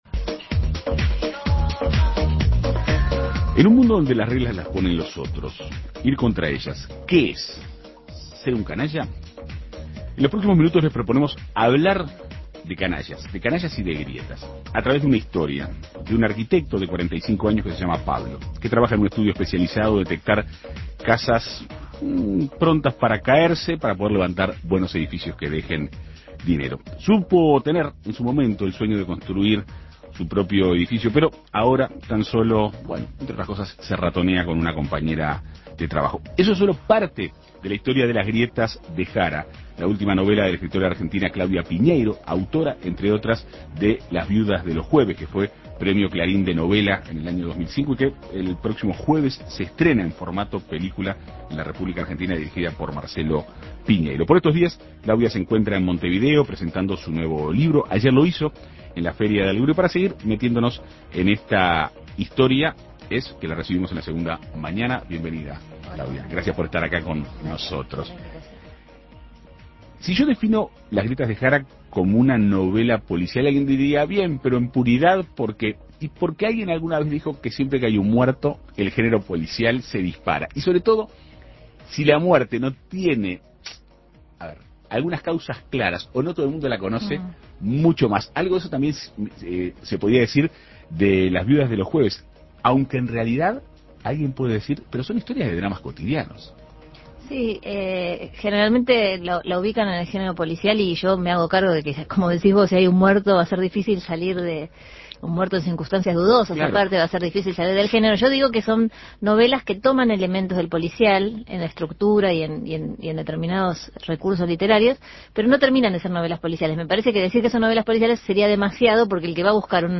En Perspectiva Segunda Mañana dialogó con la escritora.